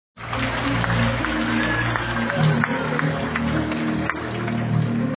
Download Clapping Cheek sound effect for free.
Clapping Cheek